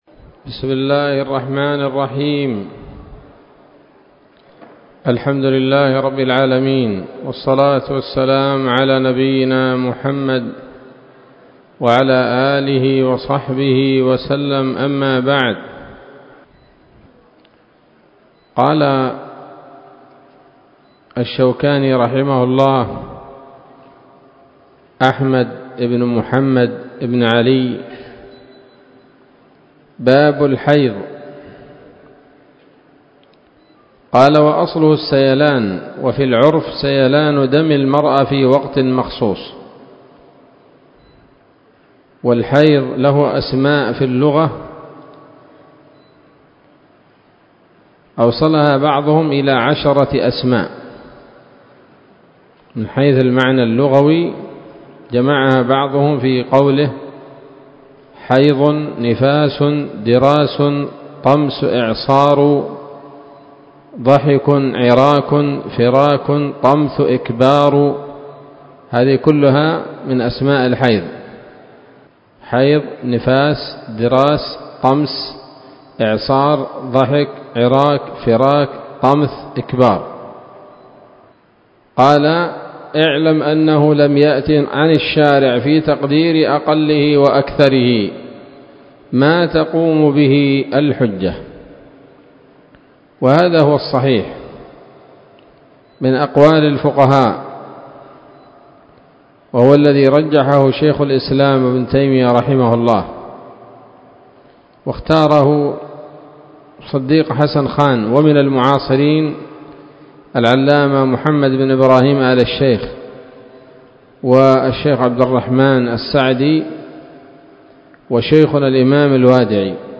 الدرس الحادي والعشرون من كتاب الطهارة من السموط الذهبية الحاوية للدرر البهية